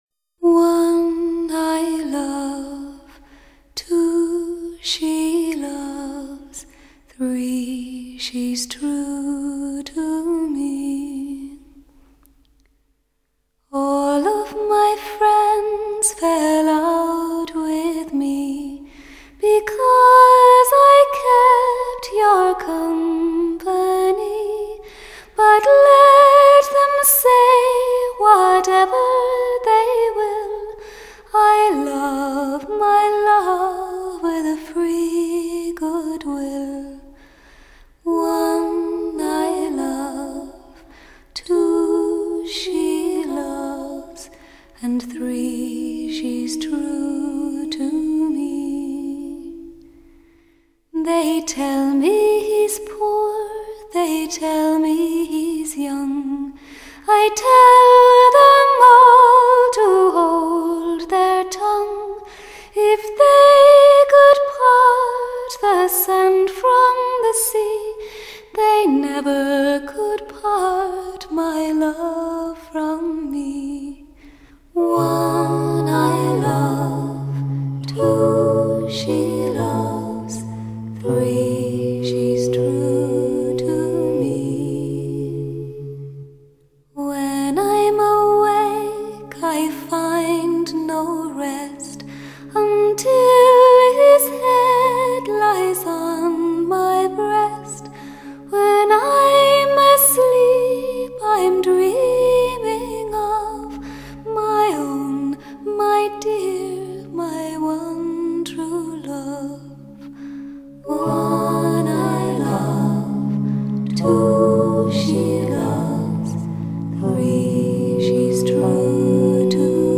天籁女音---我唯一的爱